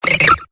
Cri de Baggiguane dans Pokémon Épée et Bouclier.